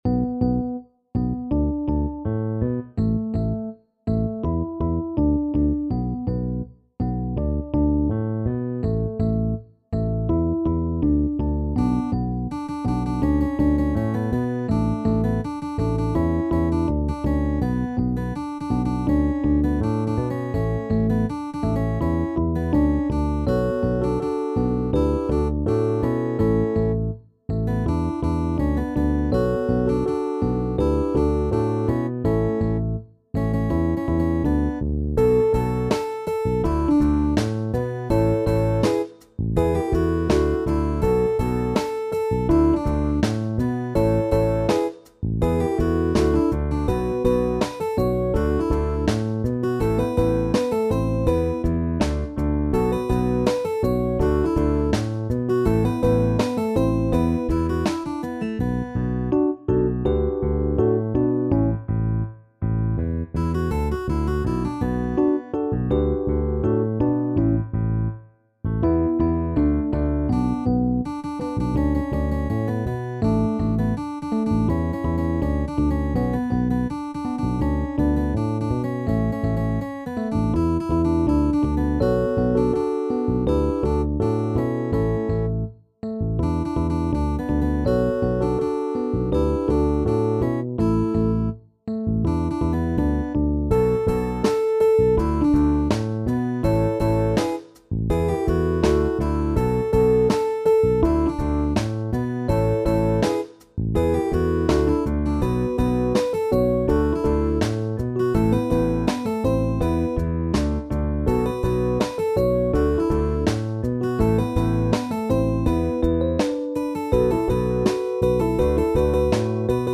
SSA mit Solo und Klavier